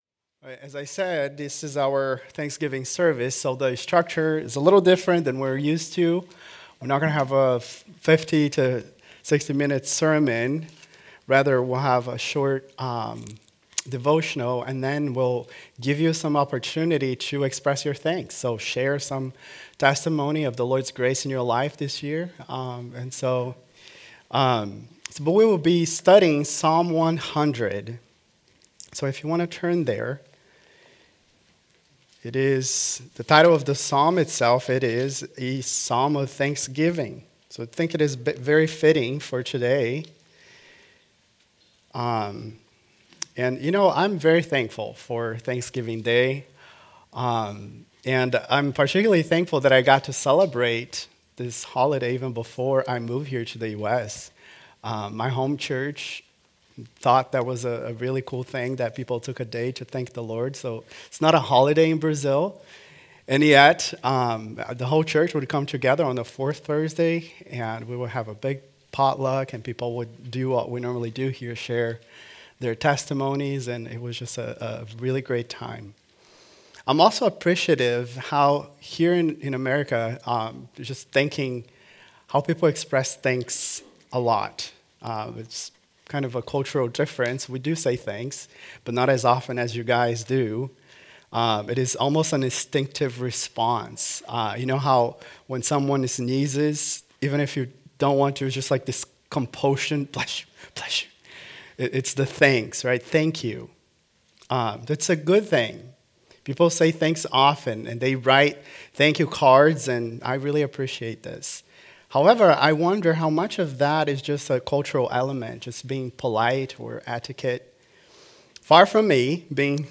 An Invitation To A Joyful Thanksgiving Sermons podcast